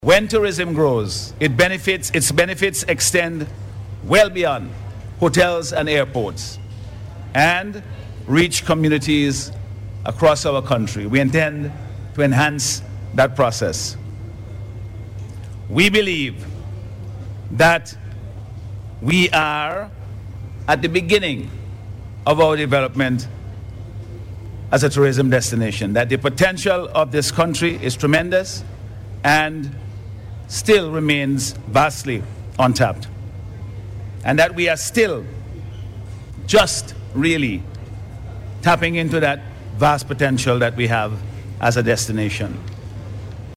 Dr. Friday made the remarks on Saturday at a special ceremony marking Delta Airlines’ inaugural flight from Atlanta, USA.